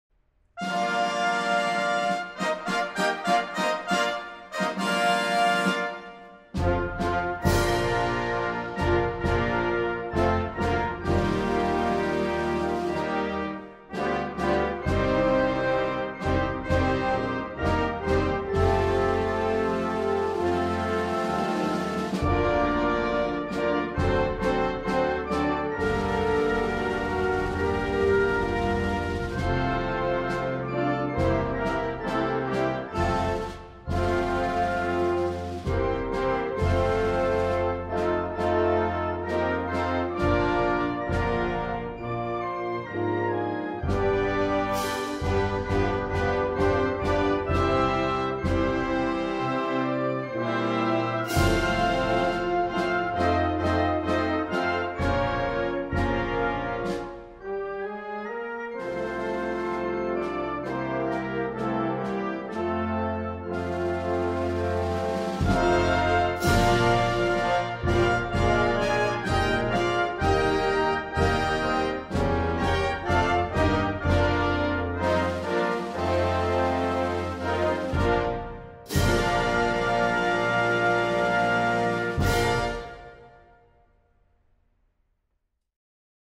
Инструментальная версия гимна Грузии